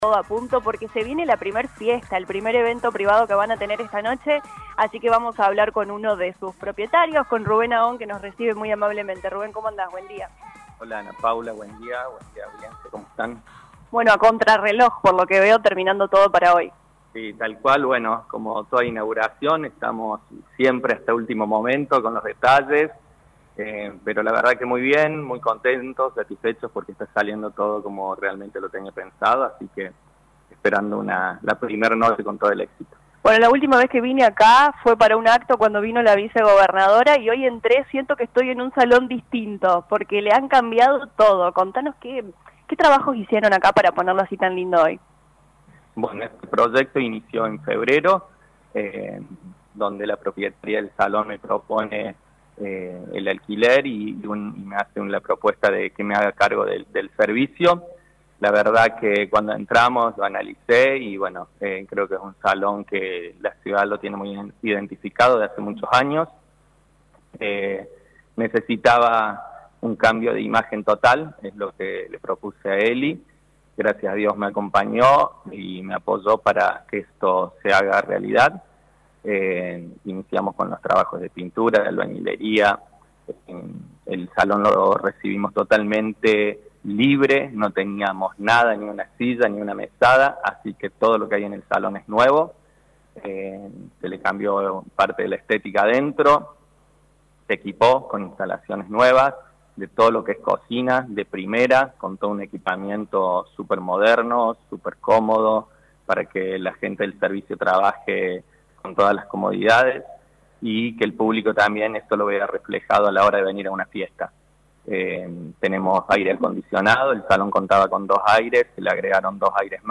La Radio 102.9 FM visitó las instalaciones de Rex Eventos